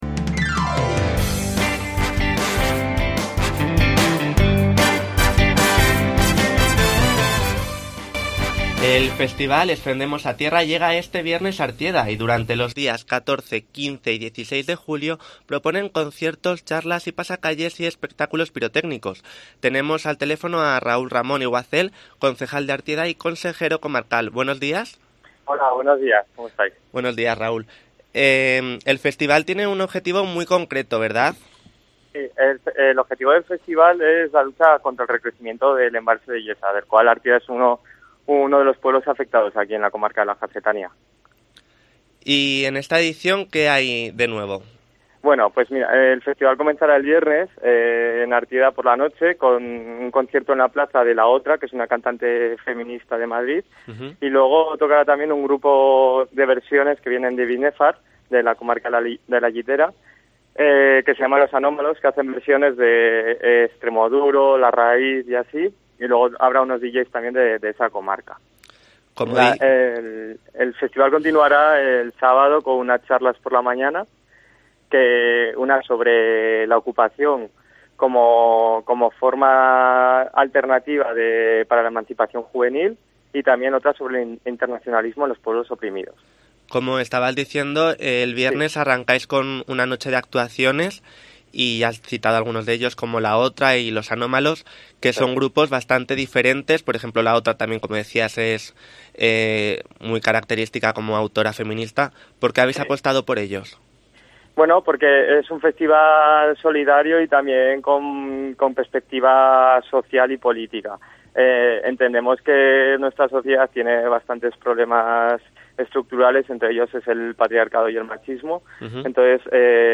Entrevista a Raúl Ramón, concejal de Artieda